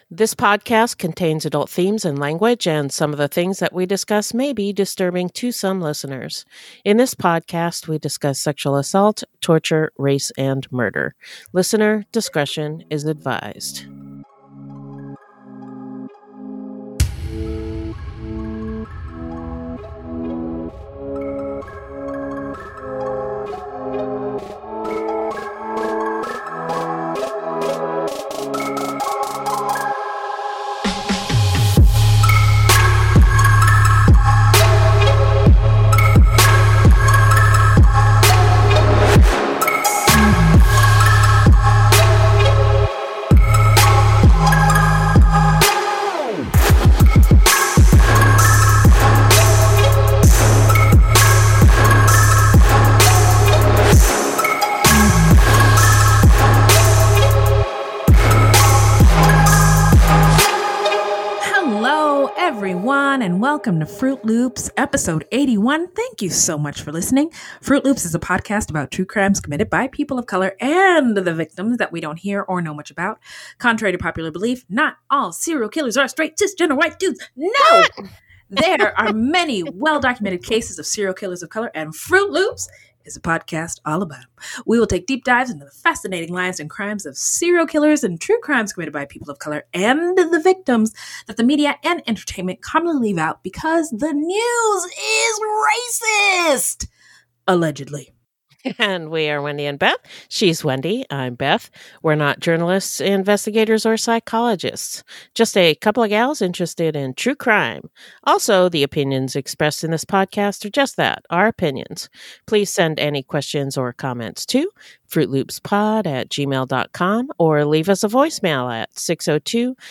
Please excuse the creepy door squeaking in the background